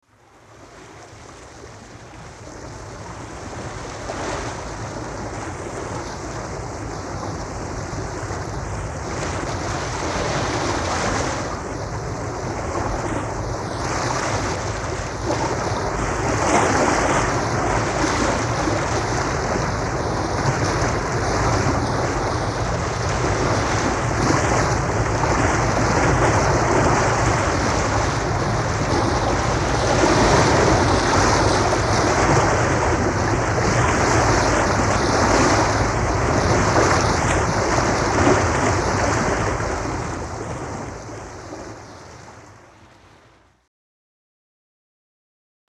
Судно в плавании
Тут вы можете прослушать онлайн и скачать бесплатно аудио запись из категории «Корабли, лодки, катера».